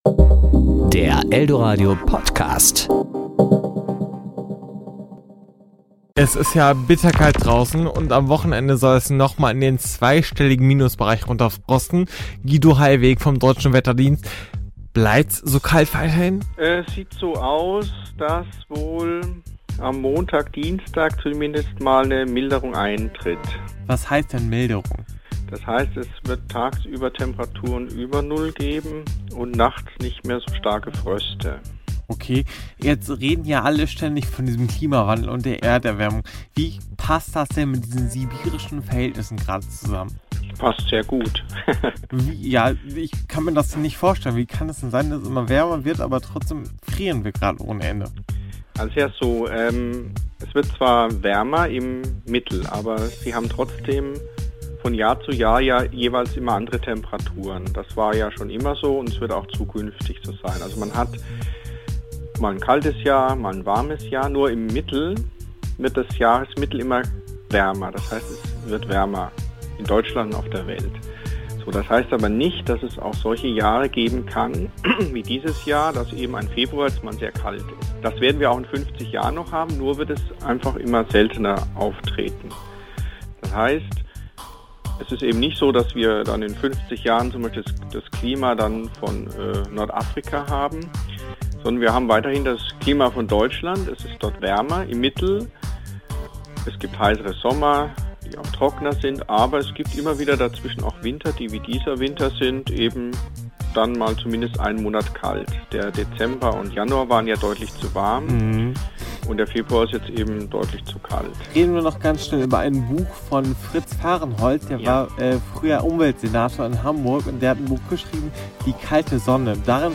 Telefoninterview: Klimawandel ja oder nein?